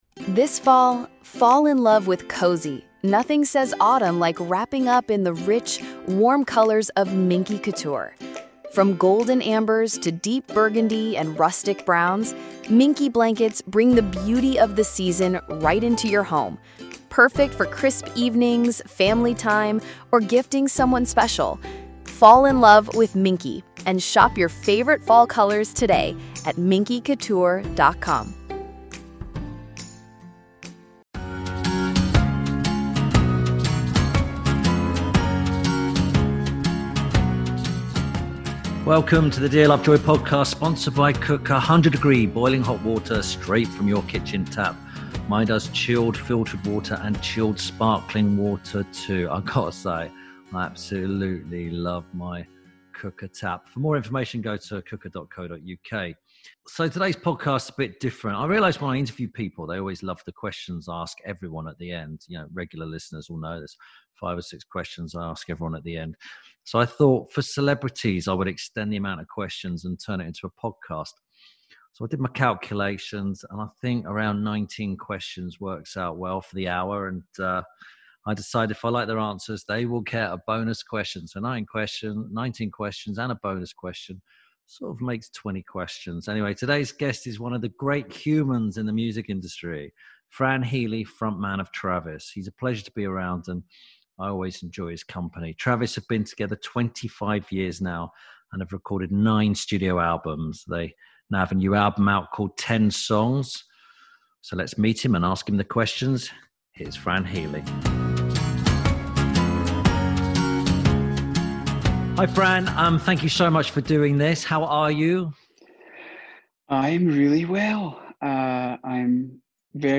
Tim Lovejoy asks Fran Healy 19 questions about his life and other stuff